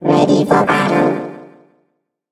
evil_rick_start_vo_02.ogg